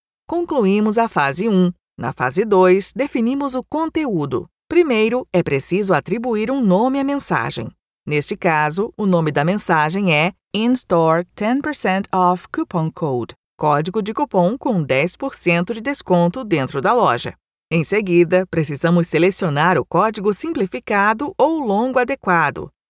Female
30s, 40s, 50s, 60s
Microphone: Shure KSM 27
Audio equipment: sound proof recording both